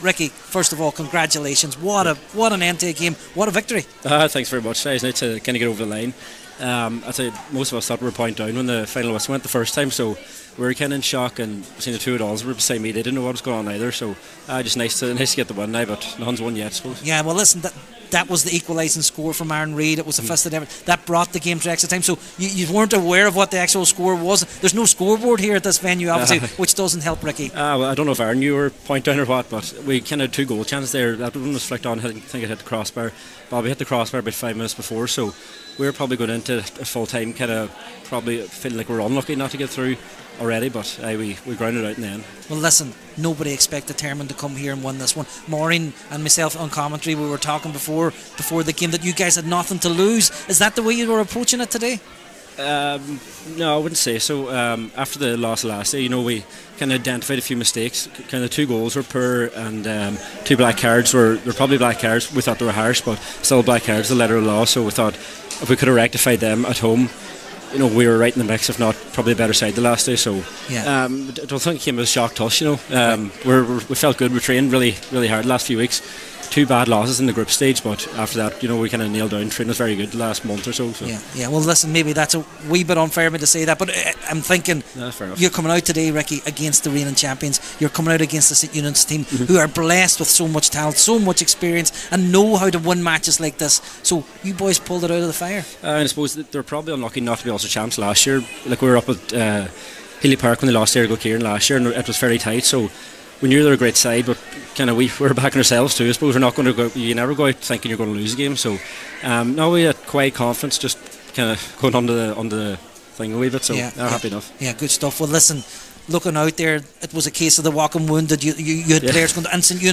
after this evening’s game